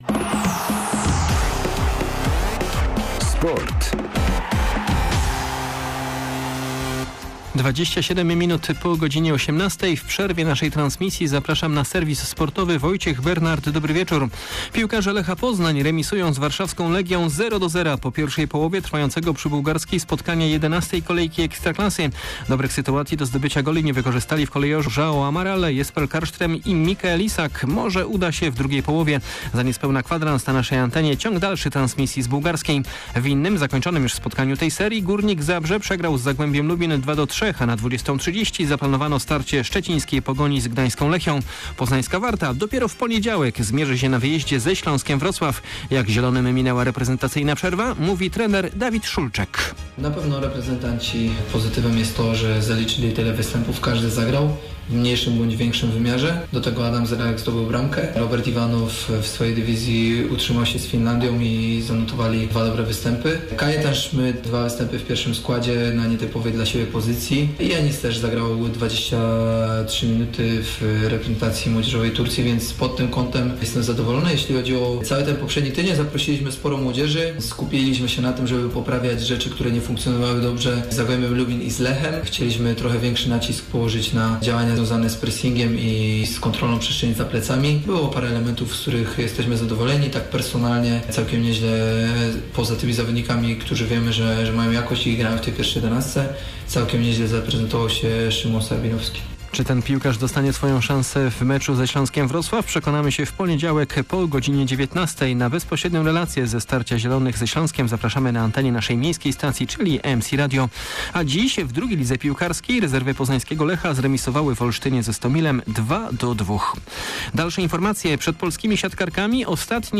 01.10.2022 SERWIS SPORTOWY GODZ. 18:27